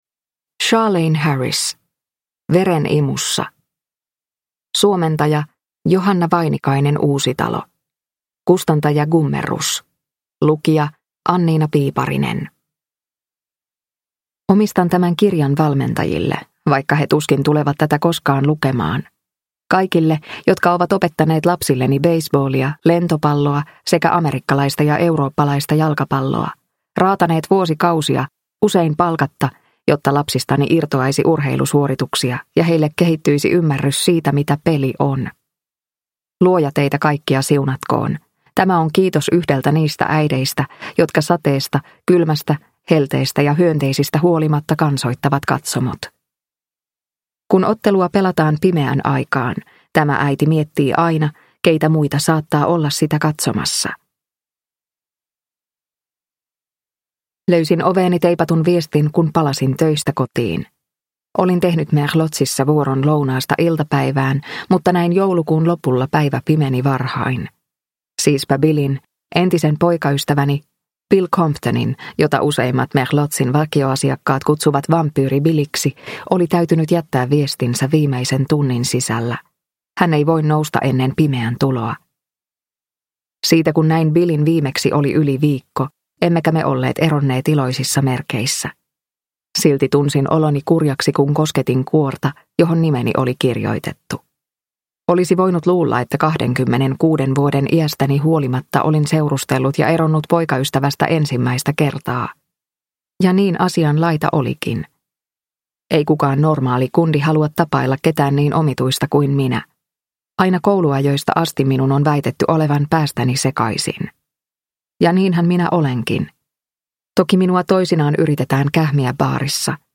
Veren imussa – Ljudbok – Laddas ner